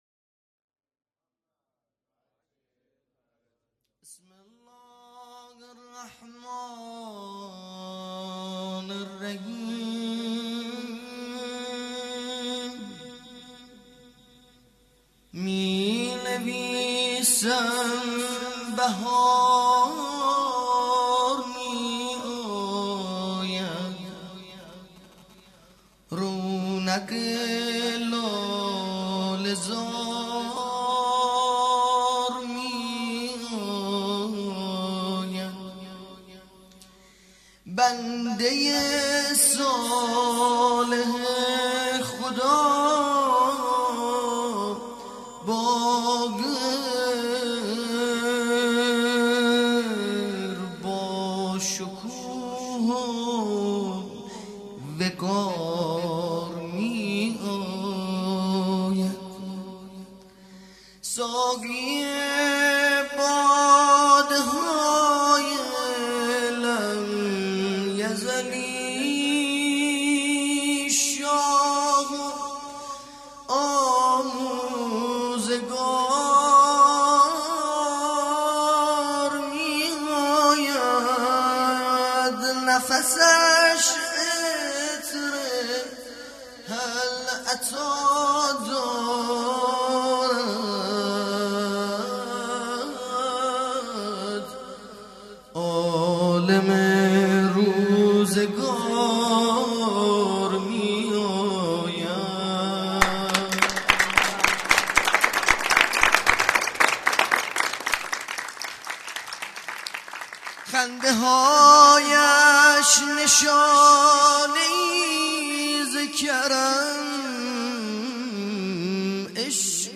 مدح
ولادت امام باقر (ع) | ۱۴ اسفند ۹۷